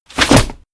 neopolitan-parasolopen.opus